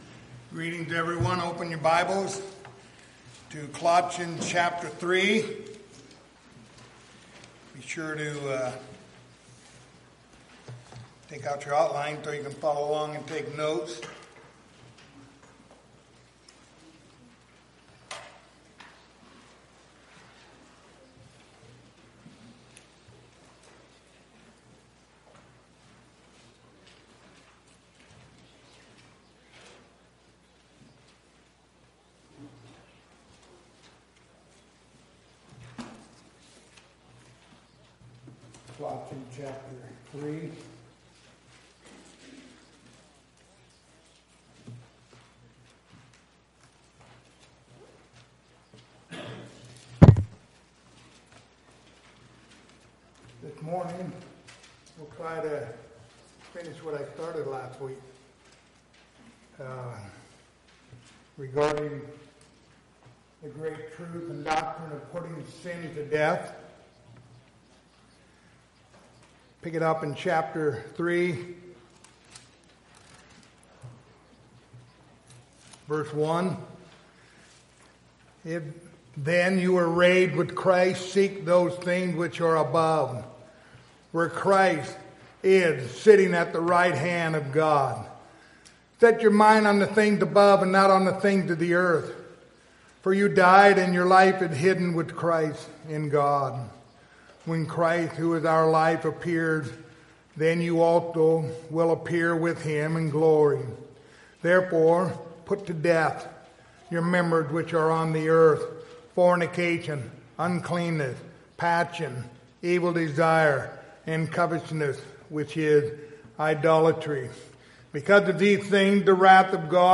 Passage: Colossians 3:5-7 Service Type: Sunday Morning